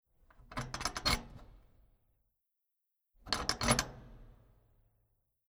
Opening and closing the metal pin
0578_Metallstift_oeffnen_und_schliessen.mp3